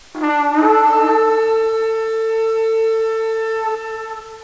wpe1.jpg (5735 byte) La tromba
shofar con cui si annunciava questo anno particolare era un corno d'ariete, in ebraico Yobel.